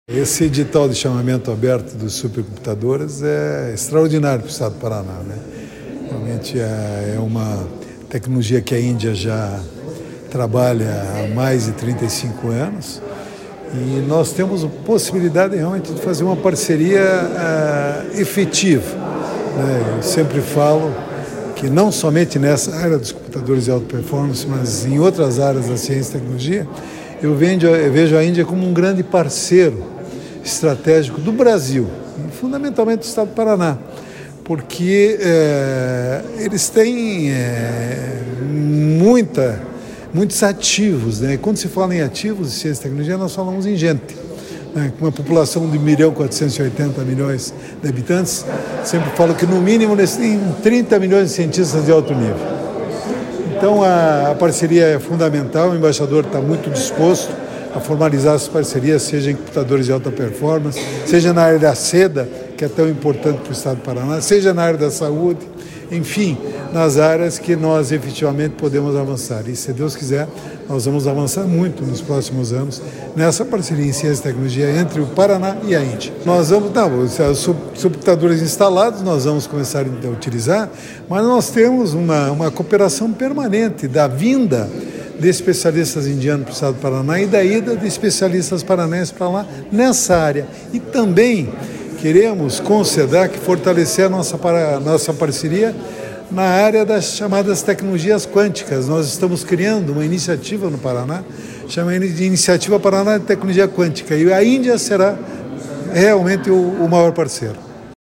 Sonora do presidente da Fundação Araucária, Ramiro Wahrhaftig, sobre reunião com novo embaixador da Índia sobre parcerias em inovação e tecnologia